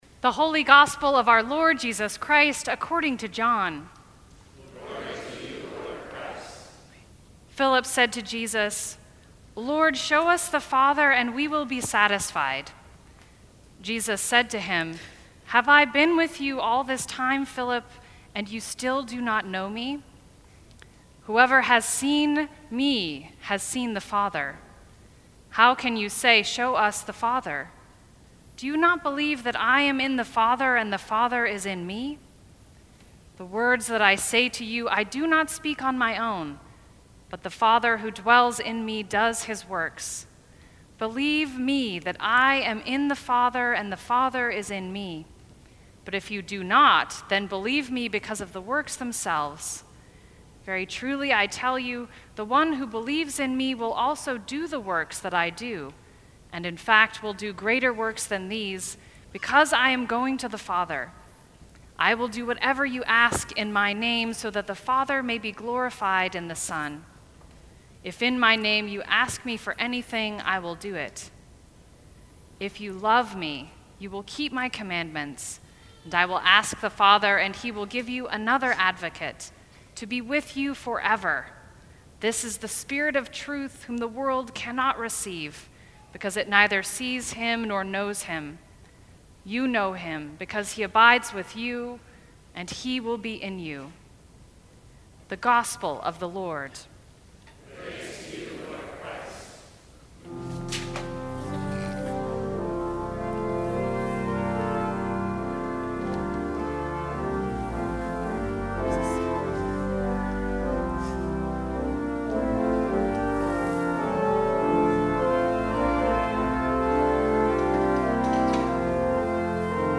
Senior Sermon